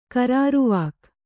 ♪ kaāruvāk